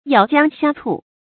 咬姜呷醋 yǎo jiāng gā cù
咬姜呷醋发音
成语注音 ㄧㄠˇ ㄐㄧㄤ ㄍㄚ ㄘㄨˋ